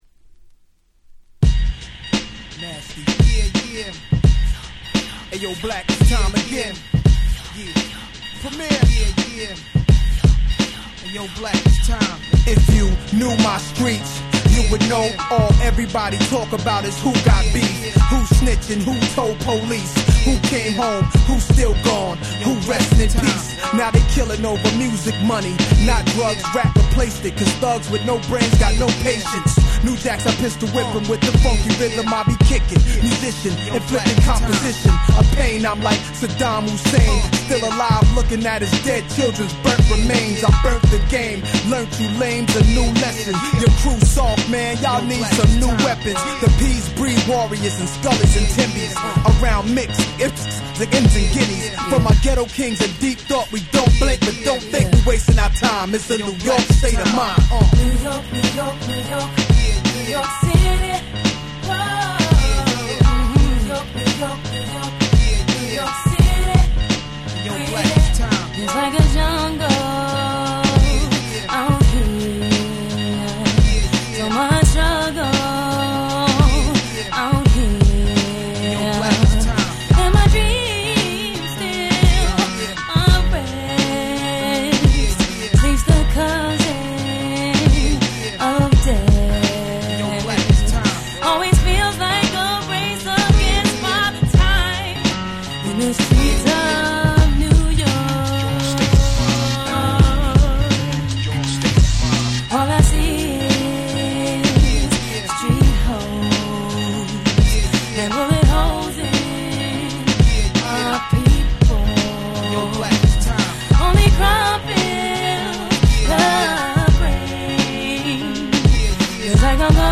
03' Nice Hip Hop !!